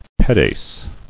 [pe·des]